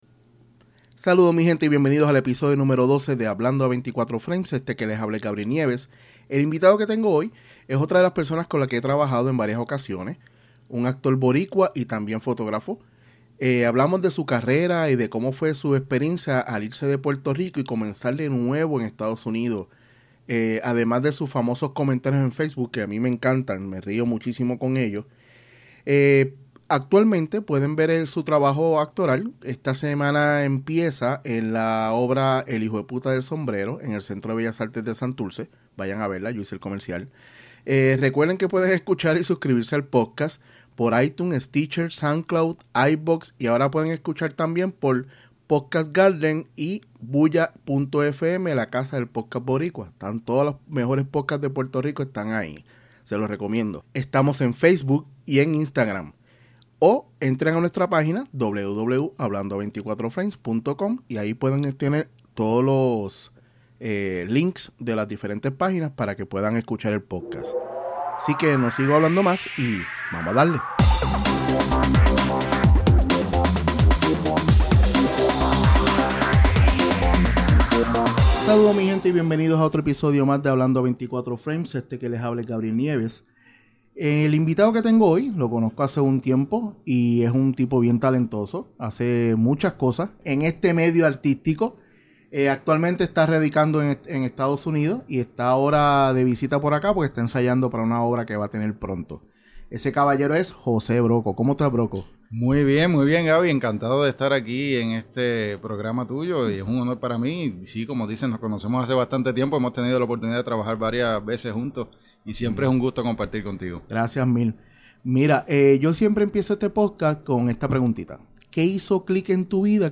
En este episodio # 12 dialogo con un actor boricua y que también se desempeña como fotógrafo, conversamos sobre su carrera y de como fue esa transición de mudarse a los Estados Unidos y comenzar nuevamente. También hablo con el de sus famosos comentarios en Facebook y su experiencia trabajando con Benicio Del Toro .